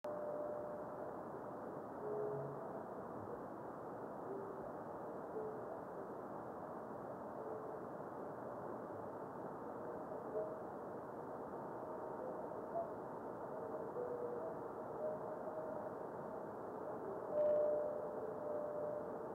Difficult to distinguish this meteor reflection.